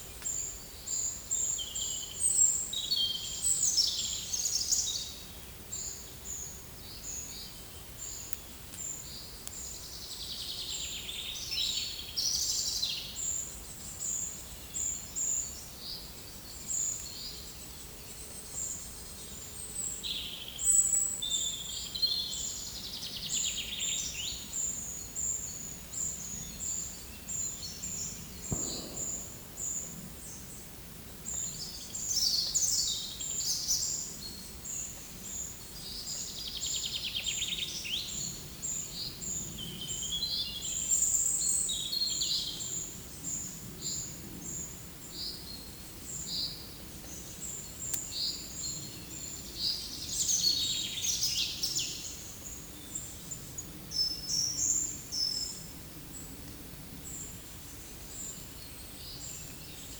Monitor PAM
Certhia brachydactyla
Certhia familiaris
Regulus ignicapilla
Erithacus rubecula